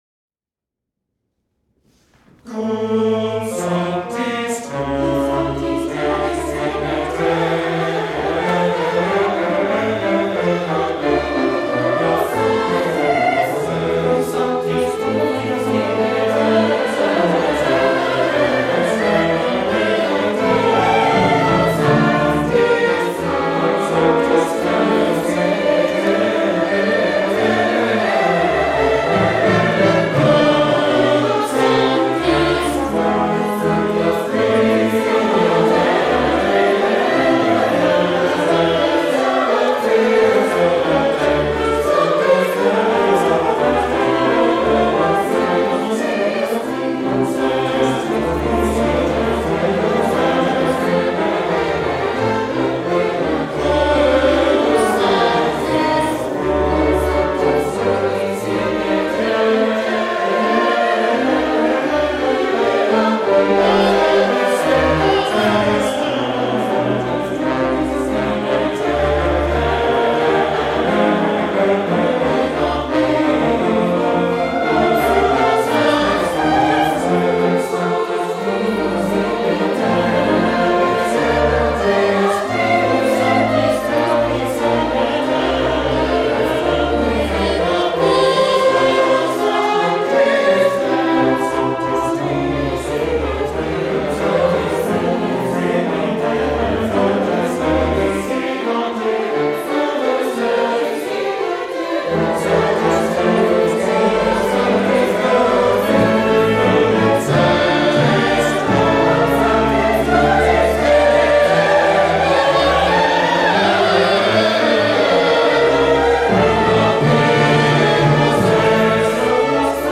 Below you will find select audio and video from our past performances for your listening and viewing enjoyment.
From our March 30, 2014 concert: